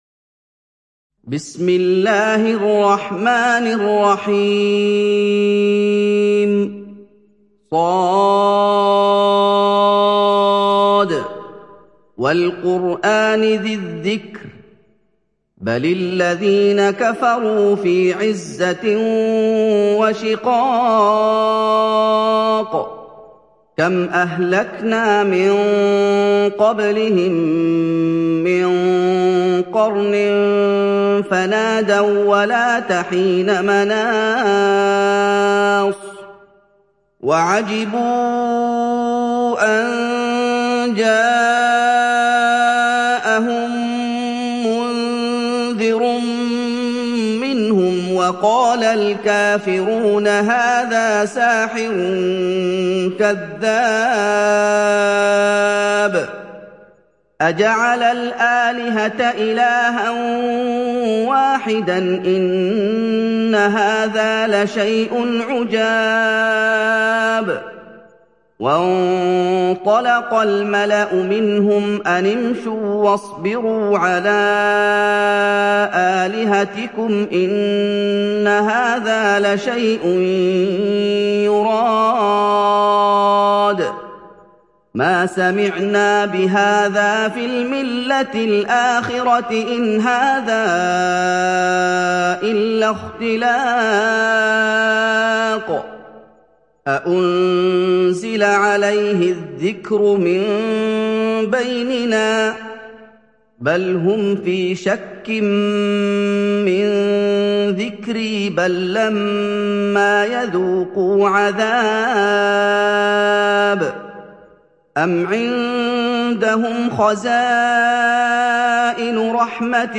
تحميل سورة ص mp3 بصوت محمد أيوب برواية حفص عن عاصم, تحميل استماع القرآن الكريم على الجوال mp3 كاملا بروابط مباشرة وسريعة